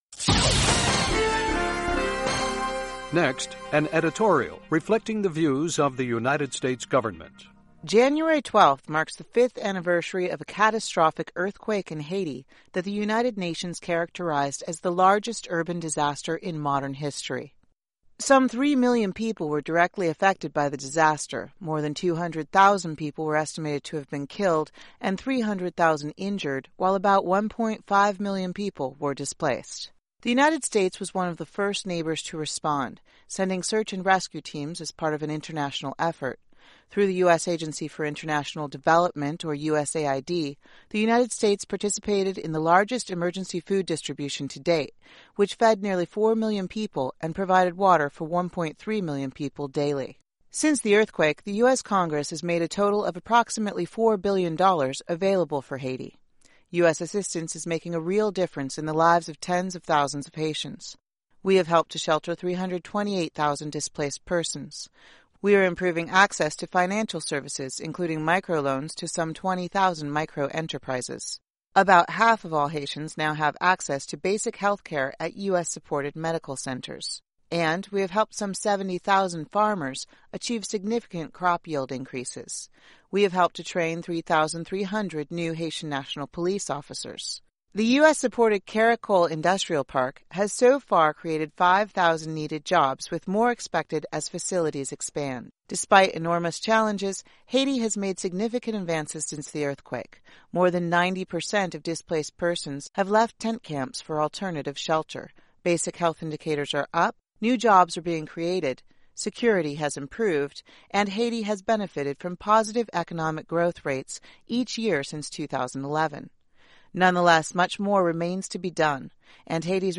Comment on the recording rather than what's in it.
Reflecting the Views of the U.S. Government as Broadcast on The Voice of America